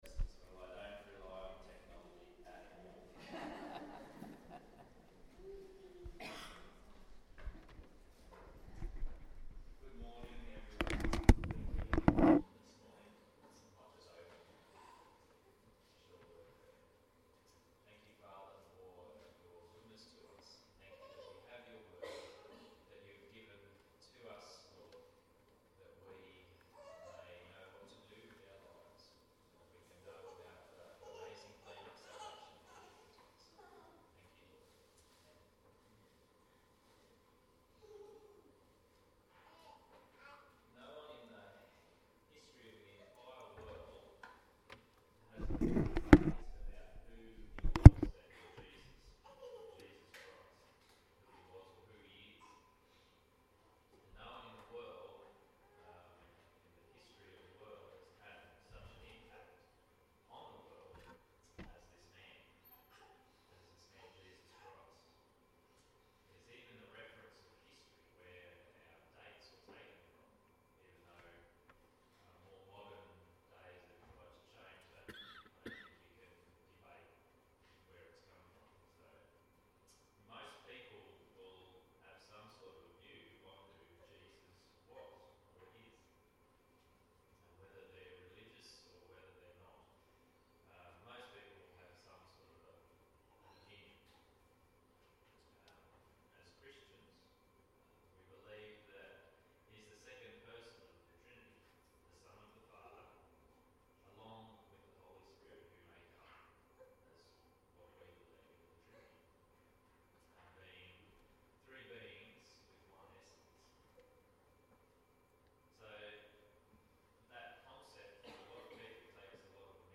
*Please skip ~10 mins of the recording as there was a technical issue and the audio is unclear*